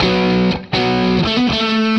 AM_HeroGuitar_120-B02.wav